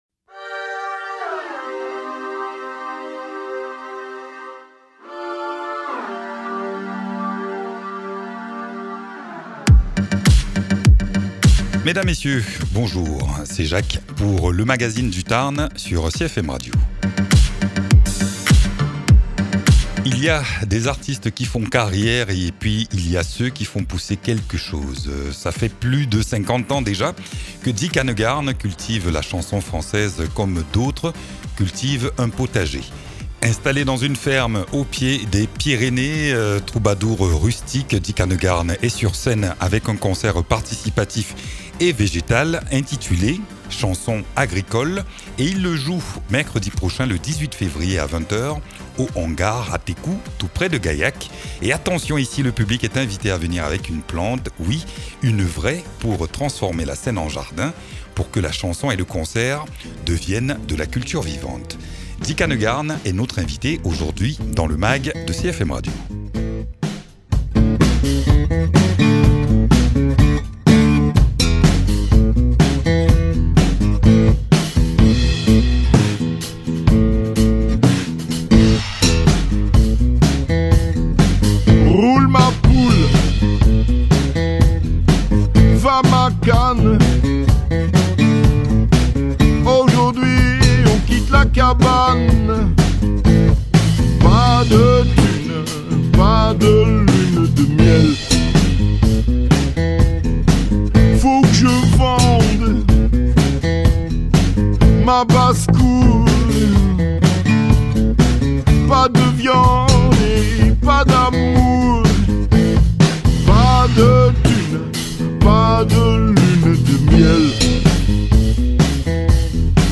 Rencontre autour d’un projet singulier, entre musique, nature et liberté.
Invité(s) : Dick Annegarn, musicien-chanteur-poète.